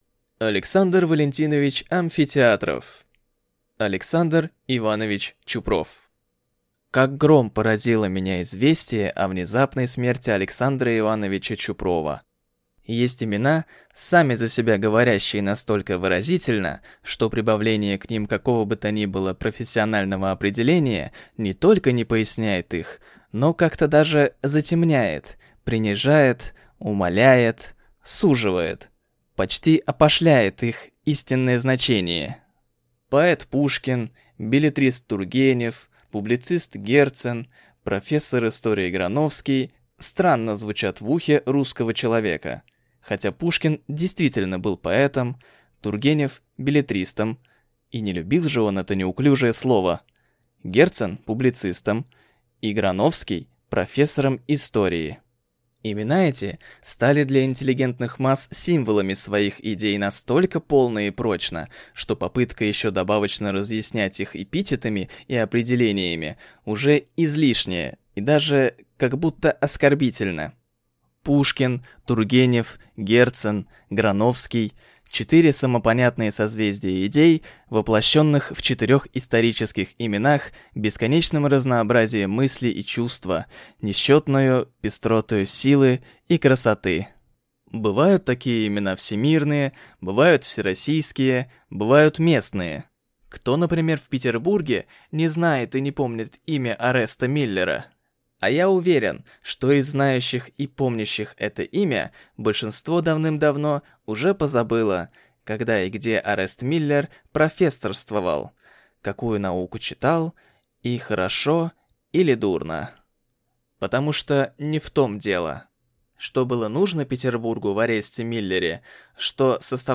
Аудиокнига Александр Иванович Чупров | Библиотека аудиокниг